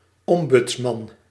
Ääntäminen
IPA: /ˈɔm.bʏts.mɑn/